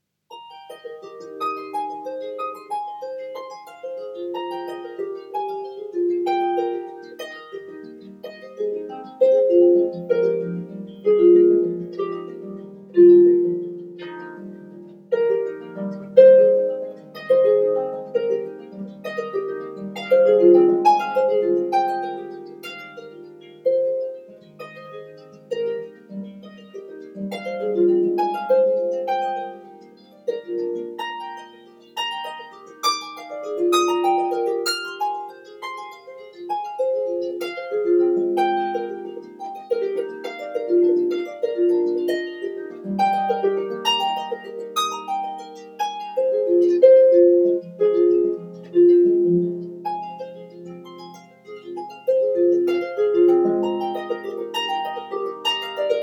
solo for lever or pedal harp